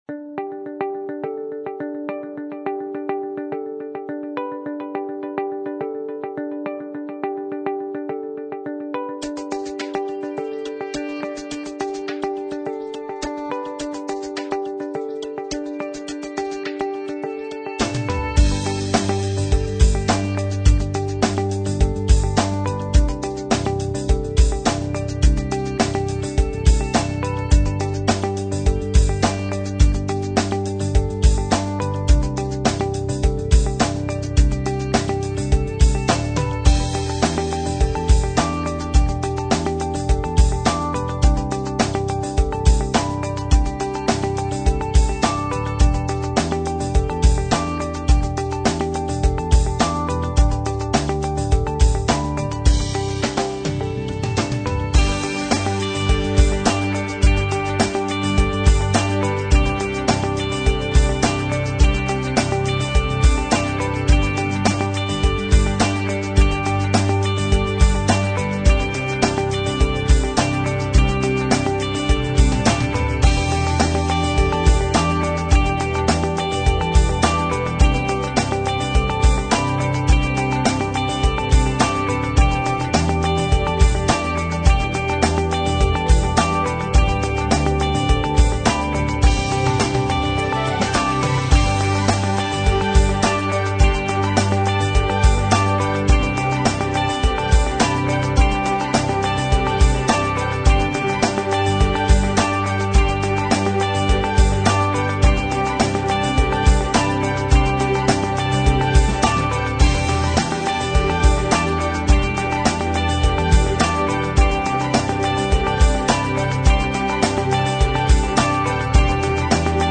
描述： 振奋人心和激励人心的流行摇滚音乐表达了很多乐观和强烈的自信。
Sample Rate 采样率16-Bit Stereo 16位立体声, 44.1 kHz